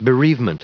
Prononciation du mot bereavement en anglais (fichier audio)
Prononciation du mot : bereavement